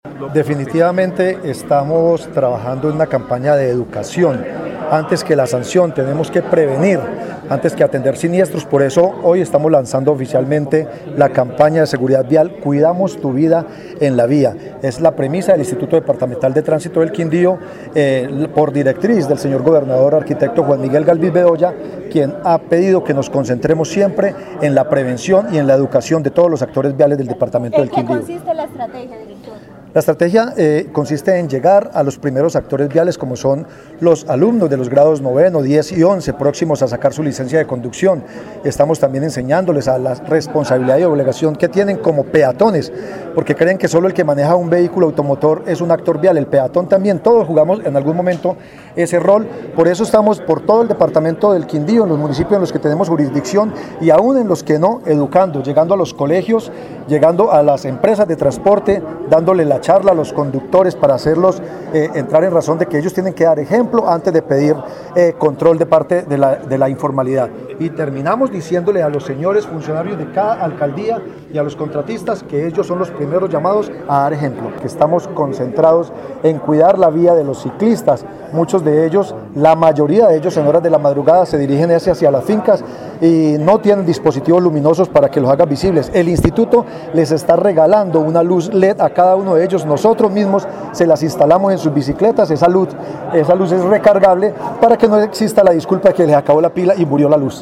Director IDTQ